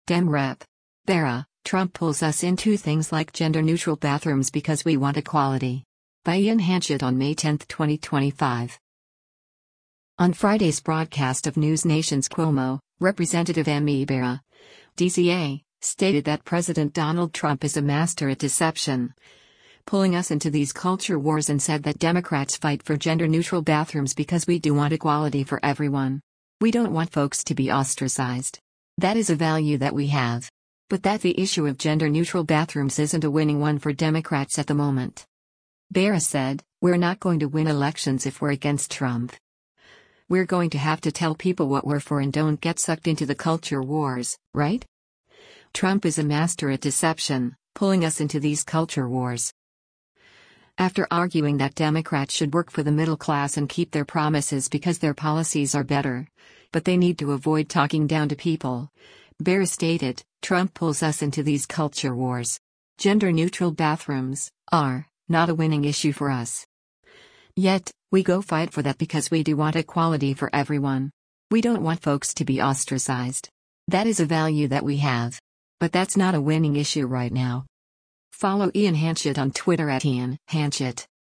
On Friday’s broadcast of NewsNation’s “Cuomo,” Rep. Ami Bera (D-CA) stated that President Donald Trump “is a master at deception, pulling us into these culture wars” and said that Democrats fight for gender-neutral bathrooms “because we do want equality for everyone.